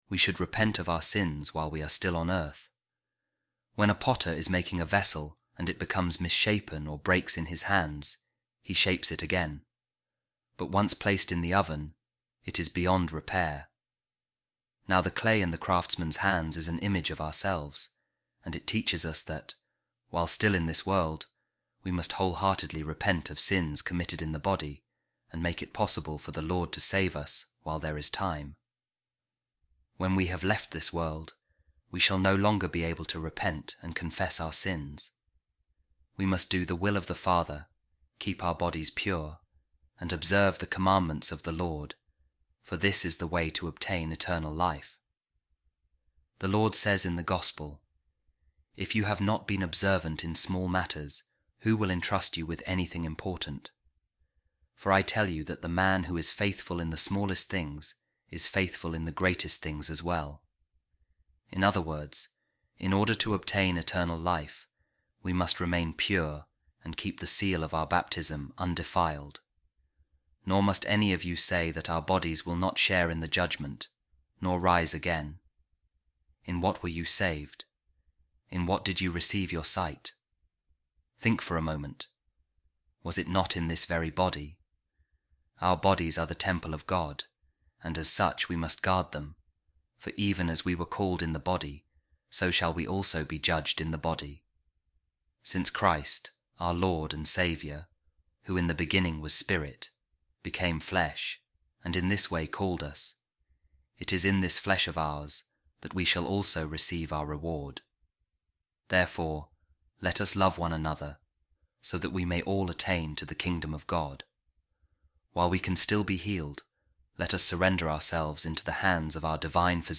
A Reading From A Homily Of A Second-Century Author | Repentance Of Sin From A Sincere Heart